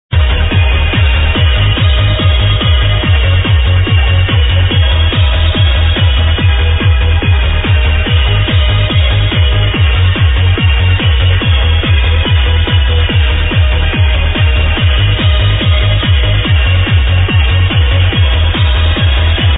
but its a new remix for sure